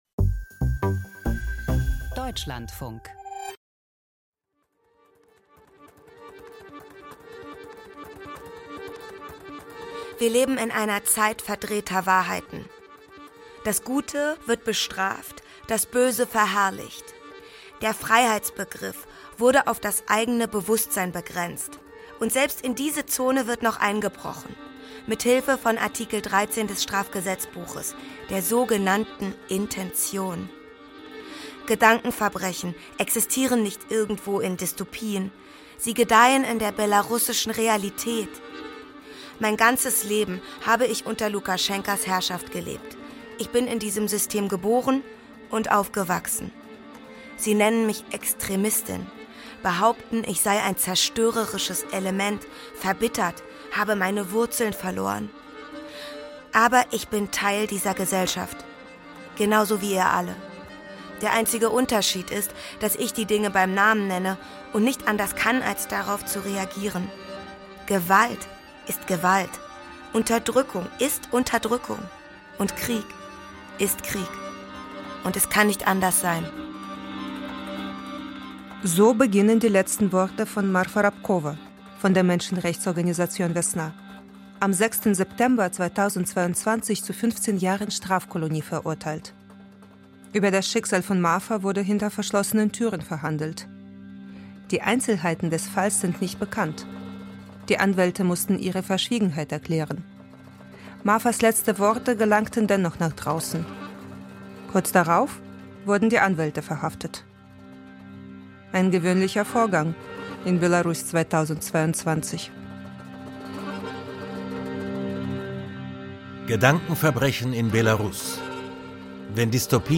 Feature Archiv Wenn Dystopien lebendig werden Gedankenverbrechen in Belarus 54:34 Minuten Die friedlichen Proteste im Jahr 2020 hätten ein Wendepunkt für Belarus sein können.